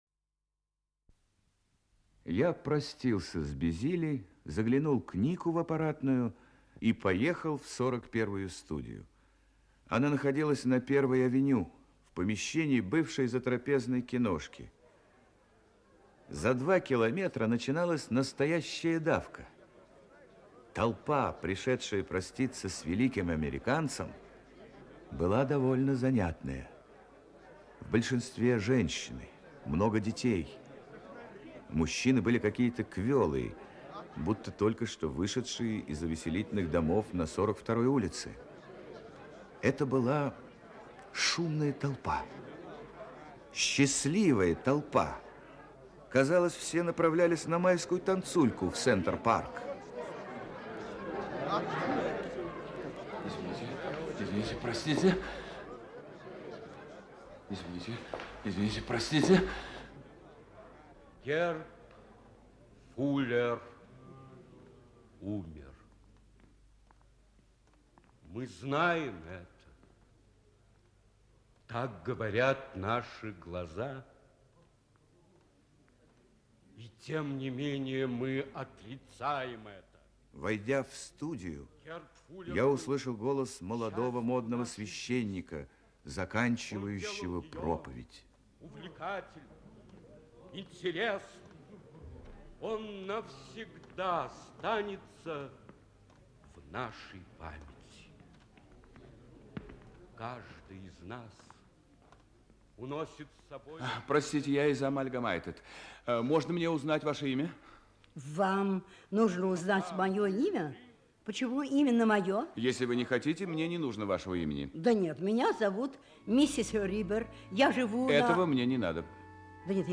Аудиокнига Взорванный миф. Часть 2 | Библиотека аудиокниг
Часть 2 Автор Эл Морган Читает аудиокнигу Актерский коллектив.